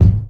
Kick
Original creative-commons licensed sounds for DJ's and music producers, recorded with high quality studio microphones.
Natural Kickdrum G# Key 54.wav
natural-kickdrum-g-sharp-key-54-ooZ.wav